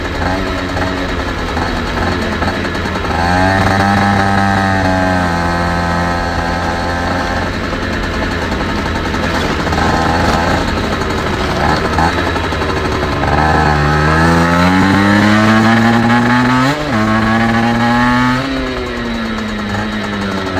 Also Ich fahre eine CPI SX 50 Ohne Krümmerdrossel Malossi VL6 Membran HD 80-82 je nach wetter ND 35 Sonst Motor Original
Hier die Audio, hab aber nicht bis in Begrenzer gedreht, stottert ca bei 7k-9k